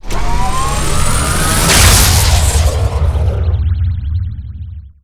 starprobelaunch.wav